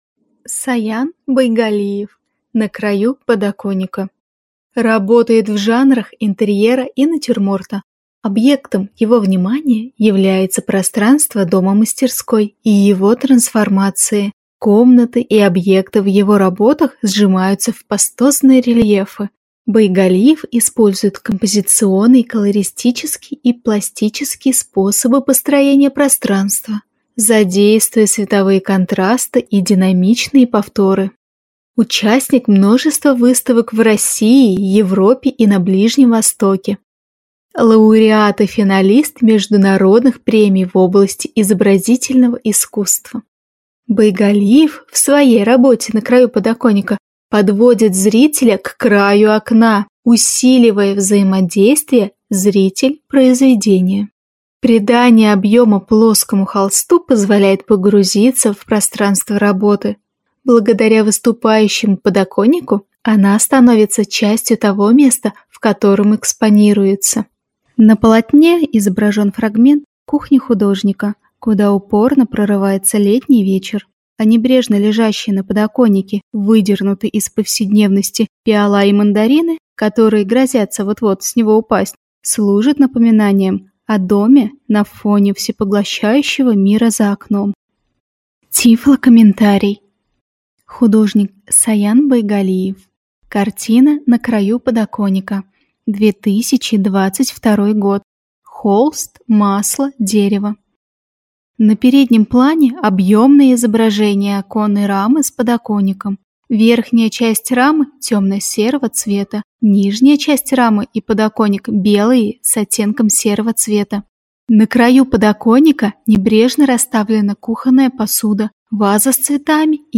Тифлокомментарий к картине Саяна Байгалиева "На краю подоконника"